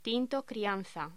Locución: Tinto crianza